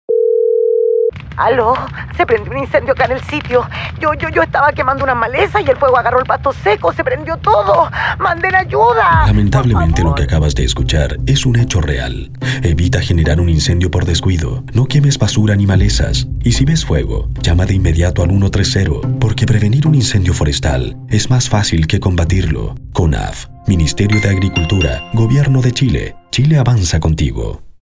Frases radiales